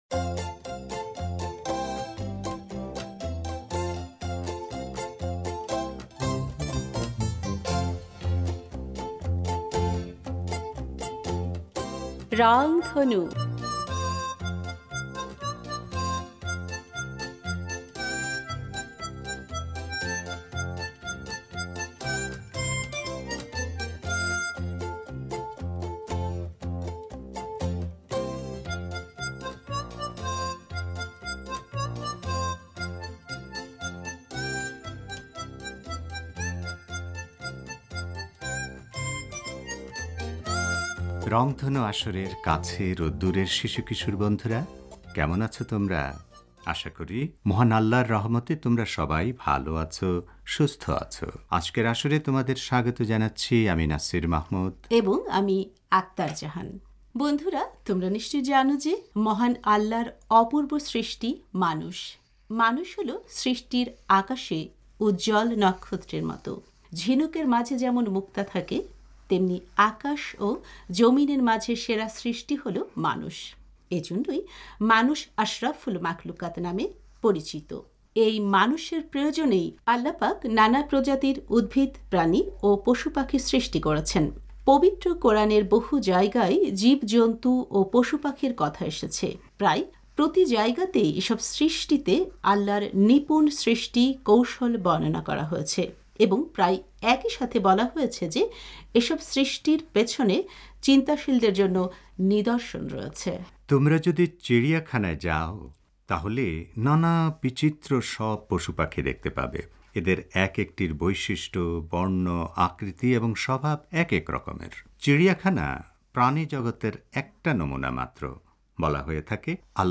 হ্যাঁ, রংধনুর আজকের আসরে আমরা পাখিদের নিয়ে একটি গল্প শোনাতে চাই। গল্পের পর থাকবে একটি গান। আর সবশেষে থাকবে ভারতের এক নতুন বন্ধুর সাক্ষাৎকার।